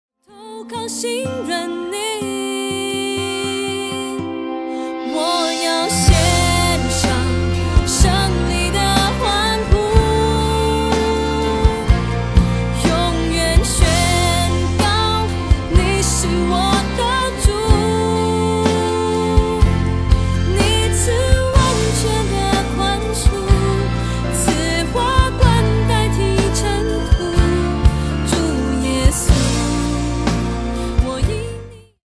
Mandarin Worship Album